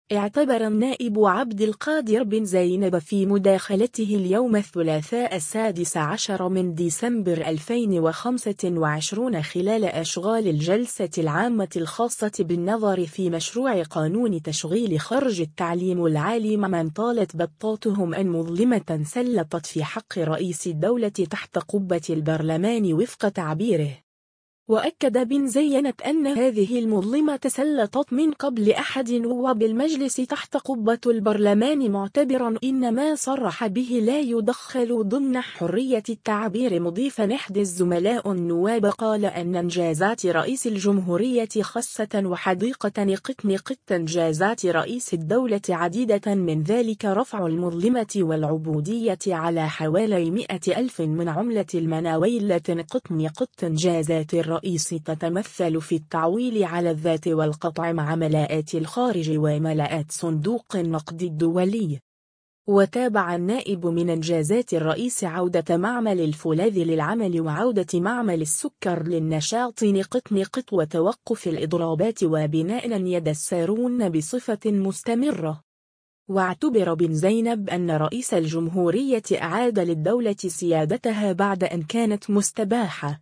خلال جلسة عامة : النائب عبد القادر بن زينب “سُلطت مظلمة في حق رئيس الدولة تحت قبة البرلمان” (فيديو)
اعتبر النائب عبد القادر بن زينب في مداخلته اليوم الثلاثاء 16 ديسمبر 2025 خلال أشغال الجلسة العامة الخاصة بالنظر في مشروع قانون تشغيل خرجي التعليم العالي ممن طالت بطاتهم أن مظلمة سلطت في حق رئيس الدولة تحت قبة البرلمان وفق تعبيره.